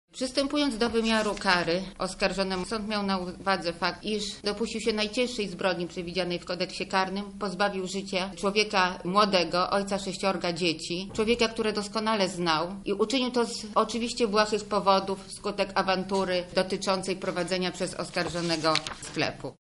– „Zgromadzone dowody były wystarczające w tej sprawie” – ogłosiła sędzia Ewa Górska-Wójcik: